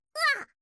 kirby_hurt.ogg